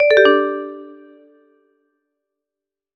mention_received.ogg